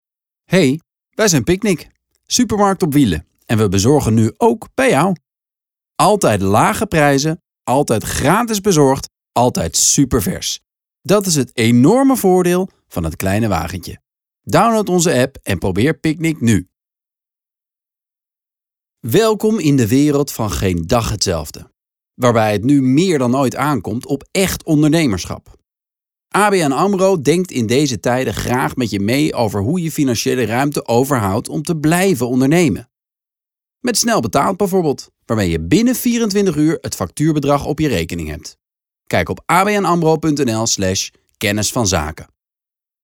Benja Bruijning is a popular Dutch actor.
Voice Demo